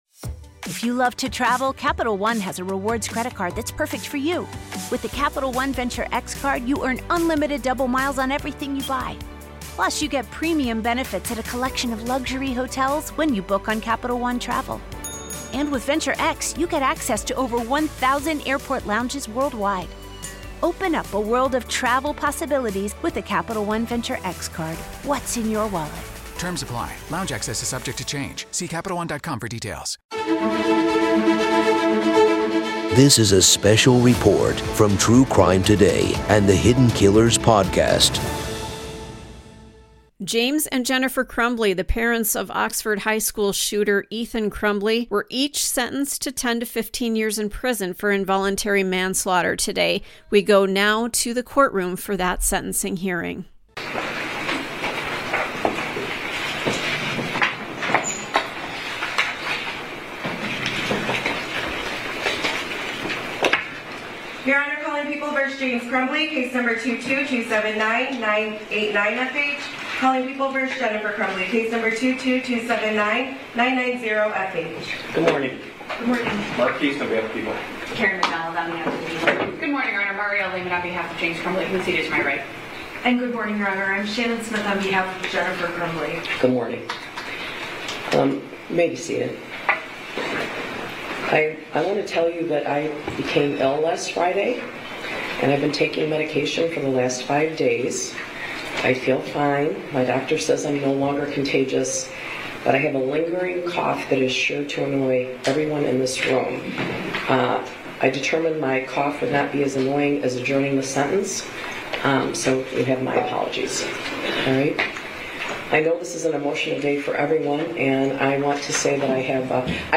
RAW Court Audio-PART 1-Parents of Oxford High School Shooter Sentenced to 10-15 Years for Involuntary Manslaughter